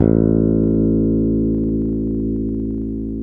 Index of /90_sSampleCDs/Roland L-CDX-01/BS _Jazz Bass/BS _Jazz Basses